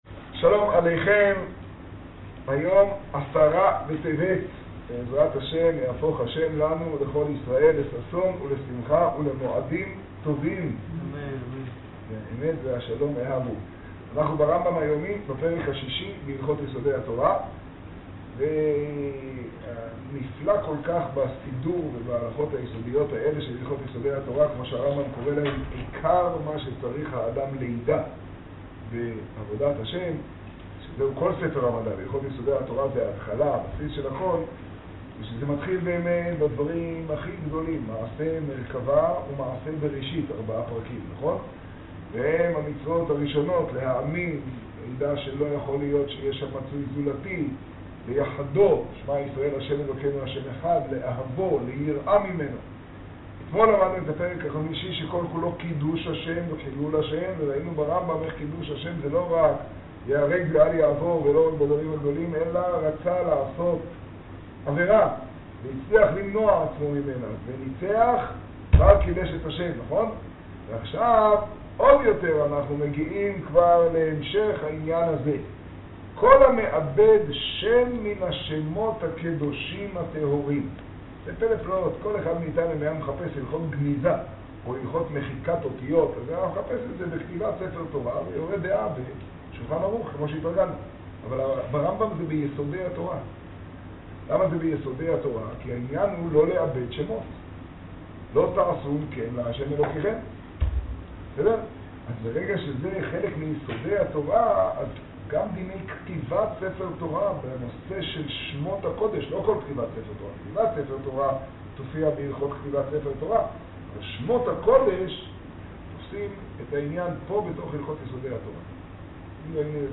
השיעור במגדל, י טבת תשעה.